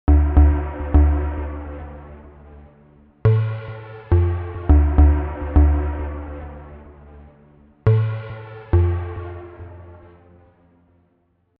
Berlin School
In following tutorial we will create some typical Berlin School-like sequences with techniques, which came up in the 1970s by using multiple analog sequencers.
This means in other words: in conjunction with the transposer track G4T4, we will play D Minor for 8 measures, thereafter G Minor, F Minor and finally D Minor again.
With G1T4 we want to play a bass sound with a lot of reverb and maybe also a phaser (as usual for ambient...).